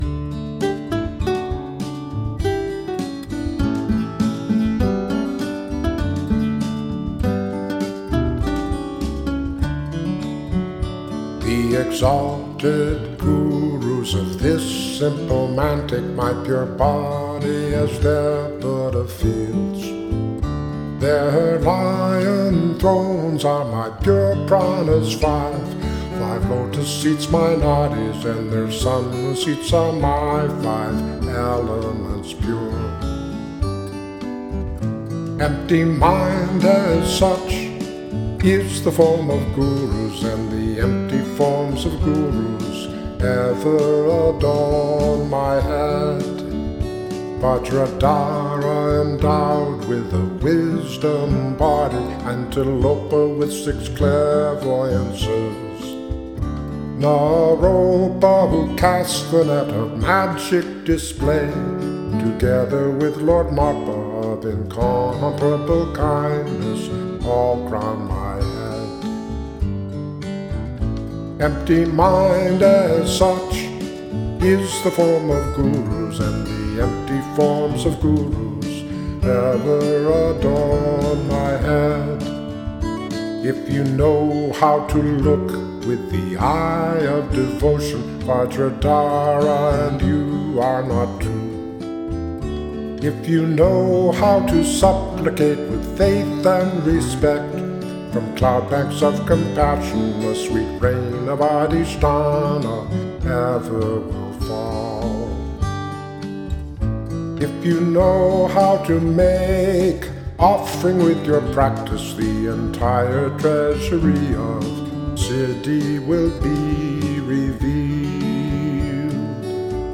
Dharma song based on the poetry of Milarepa (1052-1135)